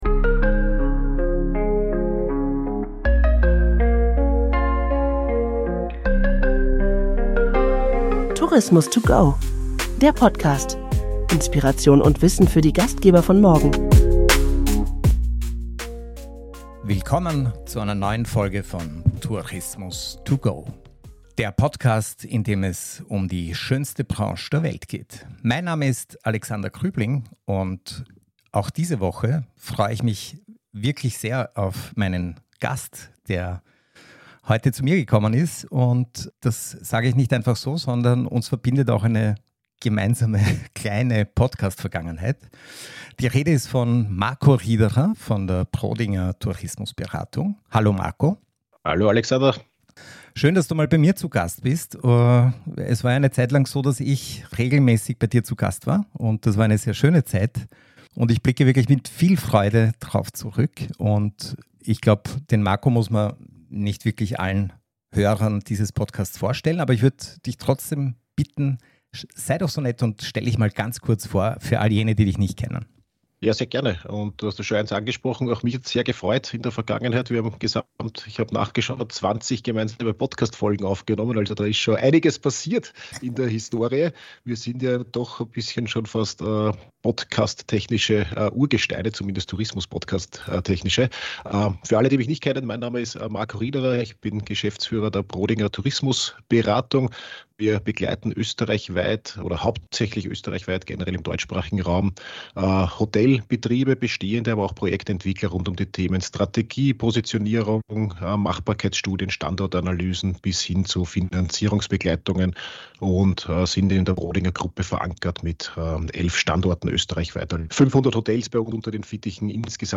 spricht Klartext, und das mit einer spürbaren Leidenschaft für die Branche.